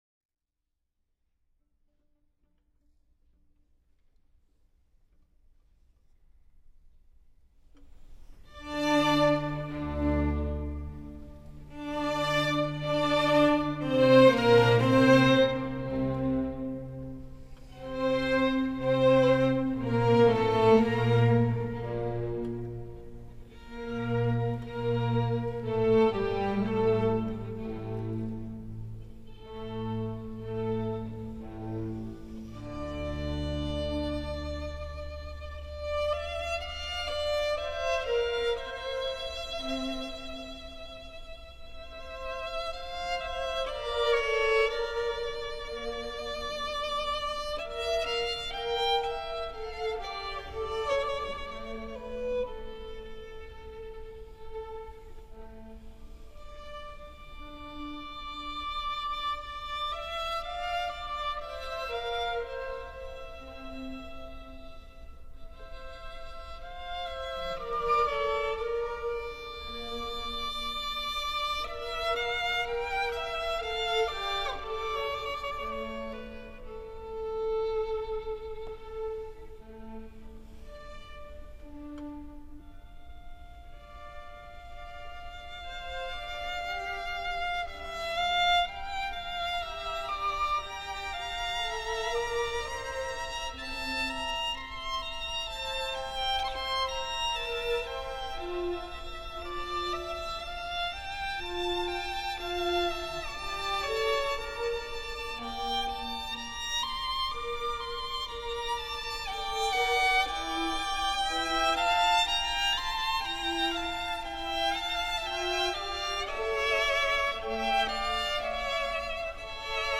Antonio Vivaldi, Concerto per 2 Violini e Orchestra in La minore.
Orchestra da Camera di Verona "Risonanza"
Chiesa di Santa Corona, Vicenza - Concerto dell'Epifania
2_Larghetto_e_spiritoso.wma